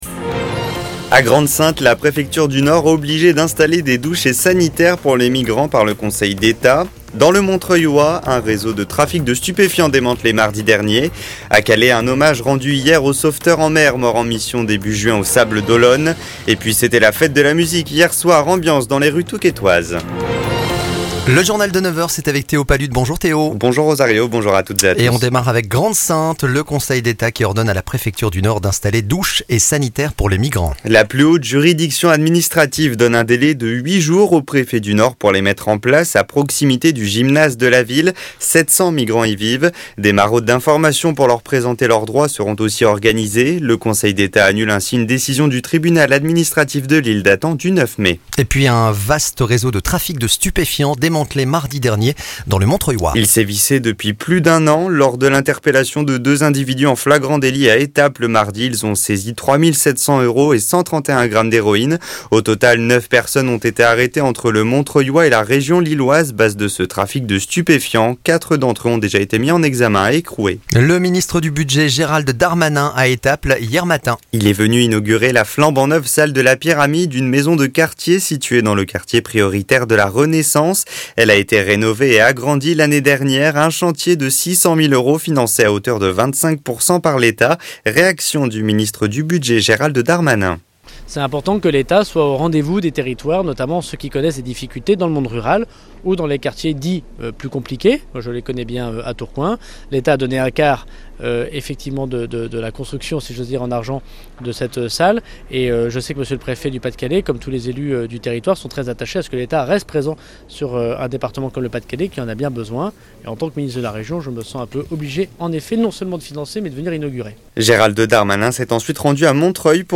Retrouvez l'essentiel de l'actualité de la Côte d'Opale et les grands titres des infos en France et dans le monde en 6 minutes. Journal de 9h.